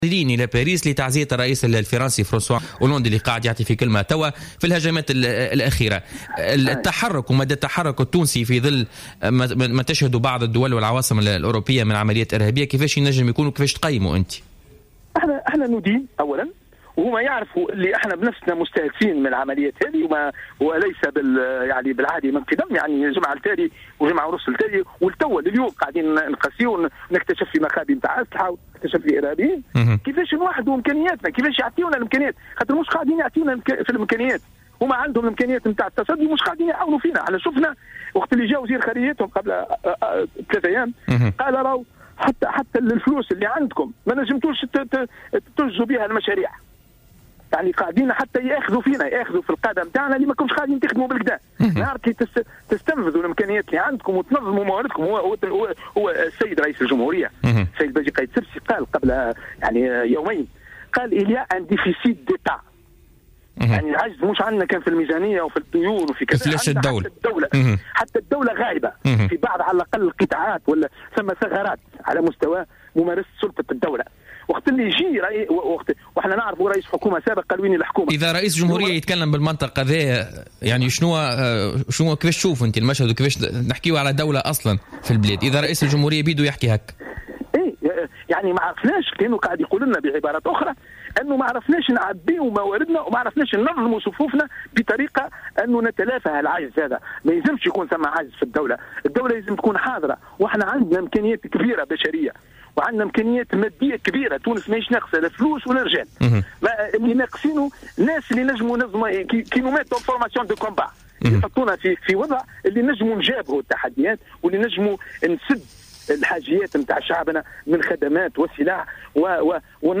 أكد القيادي بحزب نداء تونس فوزي اللومي في مداخلة له في بوليتيكا اليوم الثلاثاء 22 مارس 2016 أن الديمقراطية تسمح للجميع بتأسيس أحزاب ومغادرة حزب نحو اخر وذلك في تعليقه على اعلان حركة مشروع تونس رسميا عن حزبه.